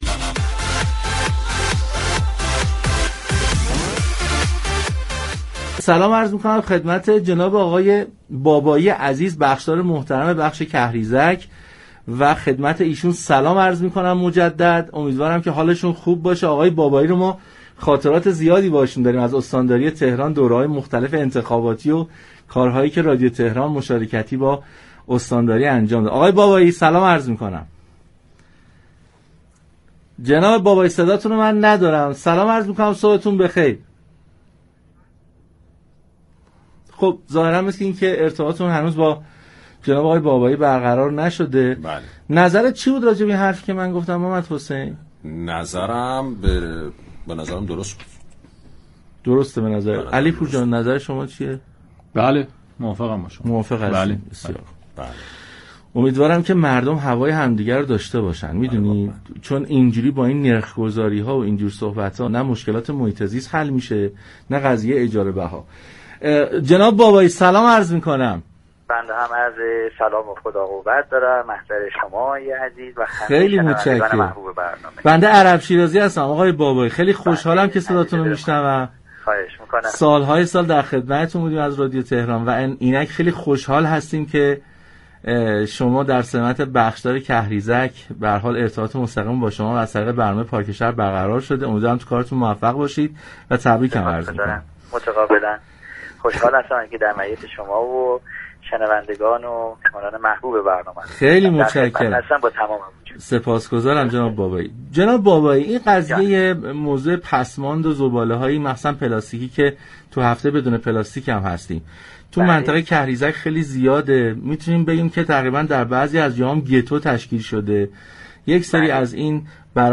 بخشدار كهریزك در گفتگو با پارك شهر از لزوم خروج زباله ها از اراضی كشاورزی كهریزك و حضور معتادان متجاهر در مراكز بازیافت زباله گفت.